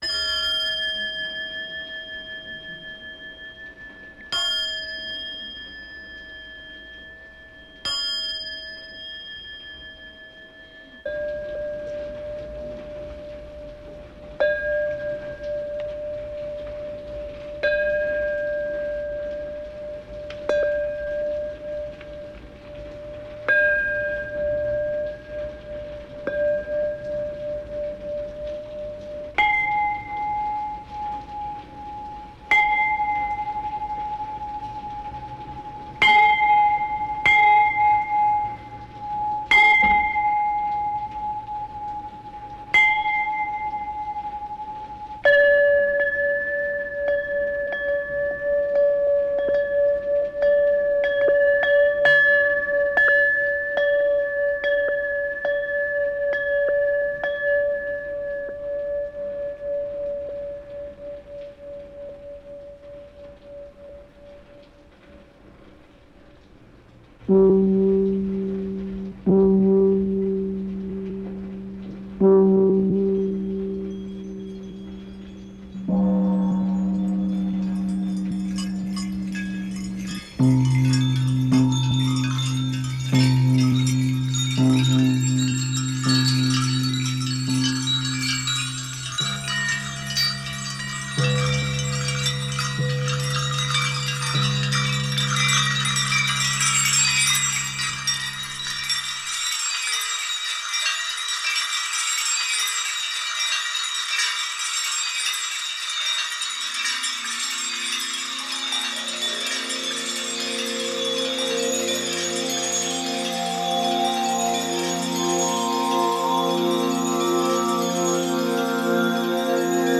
Avant-Garde Synth